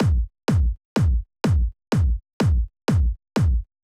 32 Kick.wav